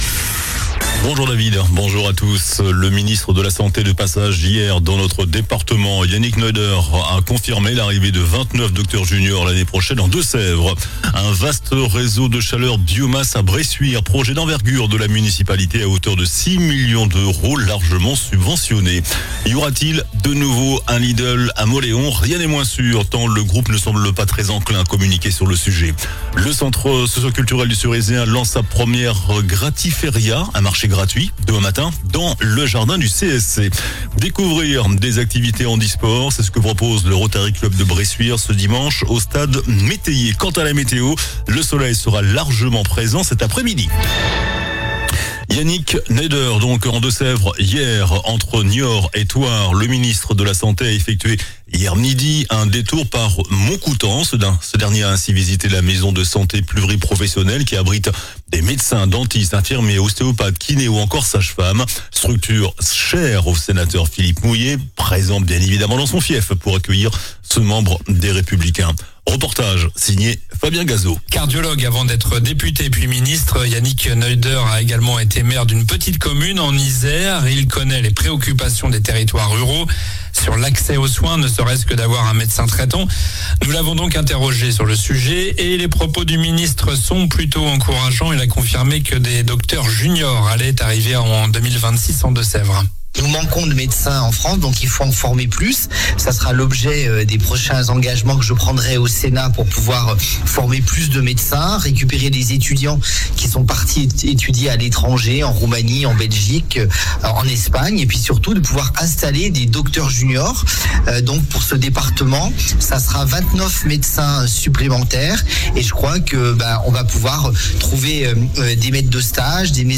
JOURNAL DU VENDREDI 23 MAI ( MIDI )